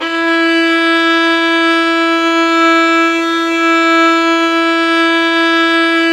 Index of /90_sSampleCDs/Roland L-CD702/VOL-1/STR_Violin 4 nv/STR_Vln4 no vib